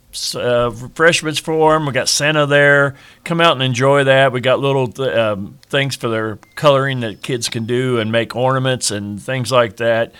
Tonight is Christmas in the Park at Eastside Park in Washington from 6 to 9pm.  Washington Mayor Dave Rhoads gives the details…